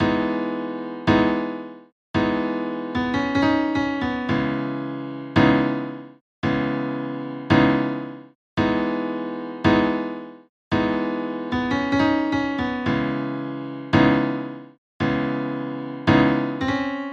Piano Thoughts 6 PT 4 Piano Melody 1 Octave Down
标签： 112 bpm RnB Loops Piano Loops 2.88 MB wav Key : D
声道立体声